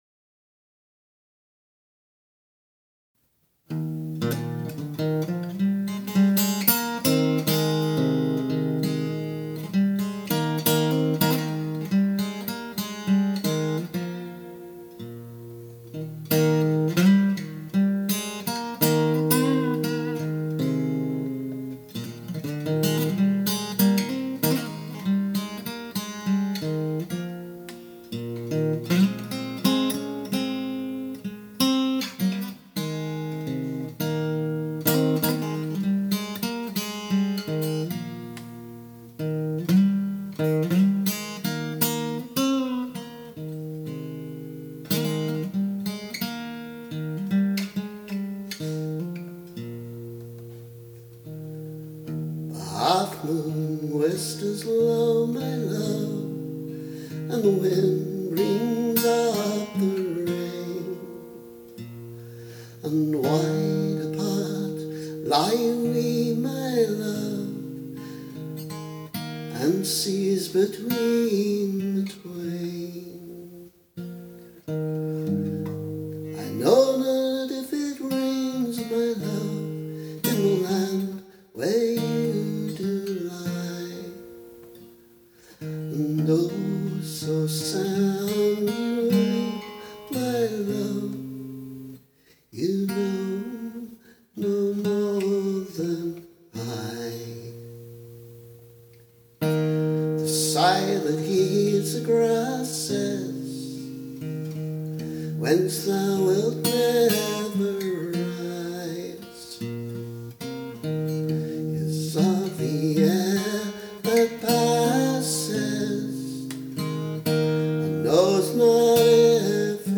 However, I’ve put a new tune to it. This is one of my ‘get-the-tune-recorded-and-worry-about-the-setting-later’ pieces, strictly a demo.